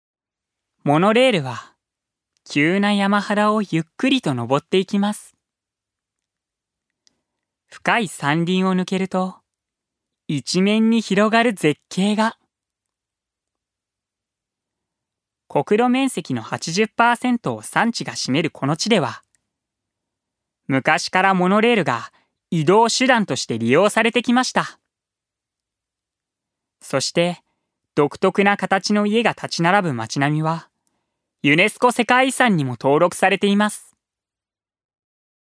ジュニア：男性
ナレーション１